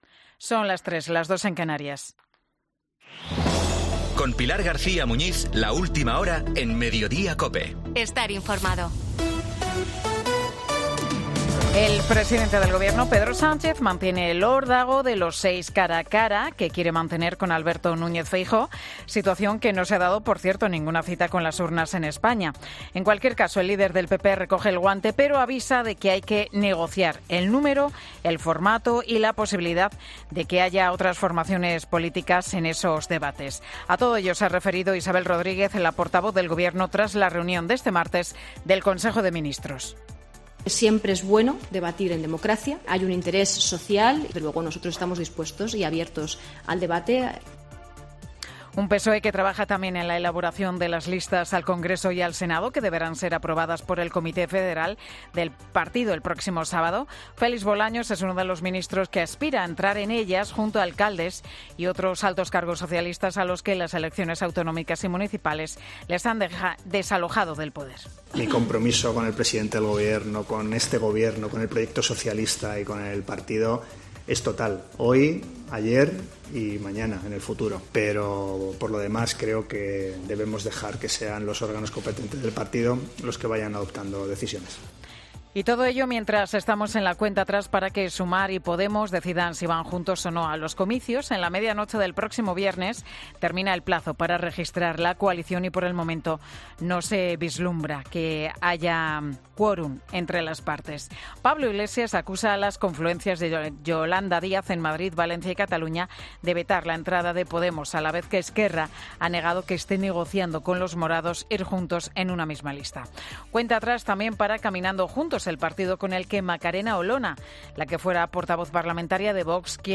AUDIO: Boletín 15.00 horas del 6 de junio de 2023 Mediodía COPE